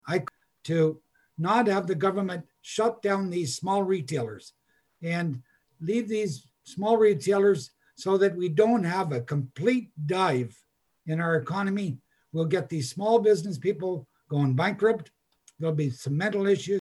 At city council’s meeting Monday, Councillor Garnet Thompson raised the issue, pointing out these types of businesses only allow a few people in store at one time as opposed to larger operations that stay open and allow larger numbers..